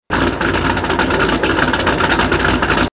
leerlauf.mp3